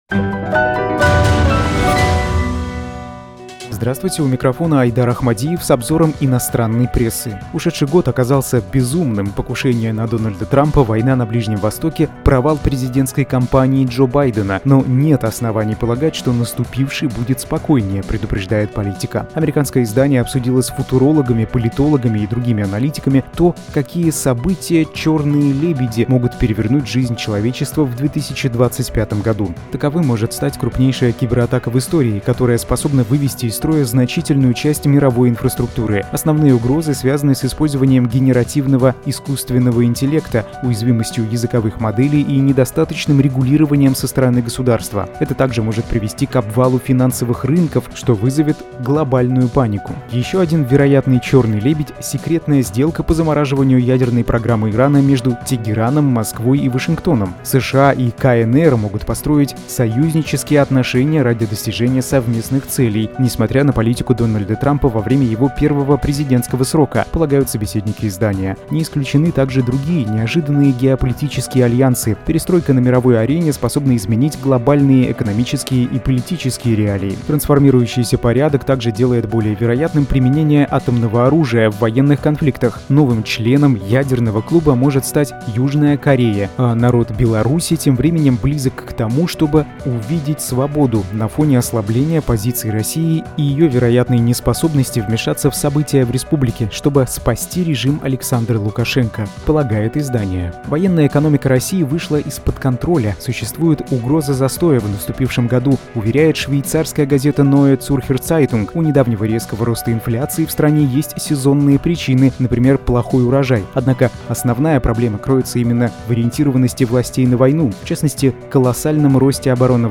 Обзор иностранной прессы 06.01.2025